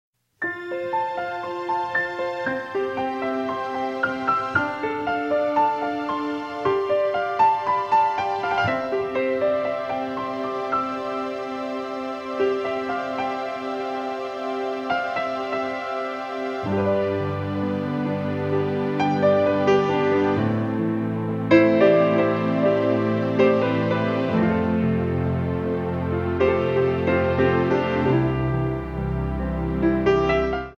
Hum along with our easy-listening country piano music CDs.